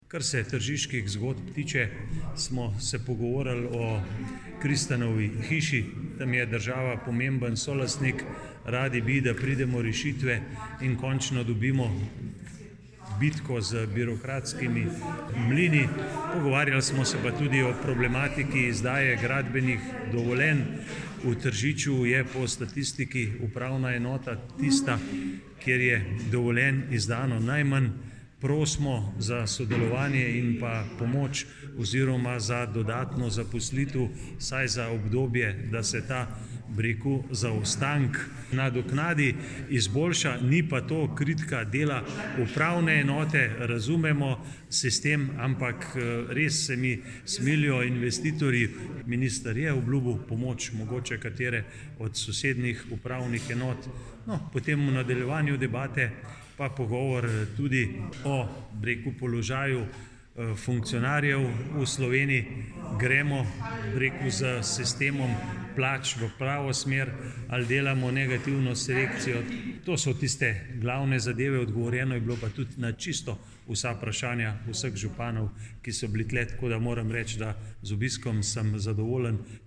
izjava_mag.borutsajovic_obiskvlade.mp3 (1,9MB)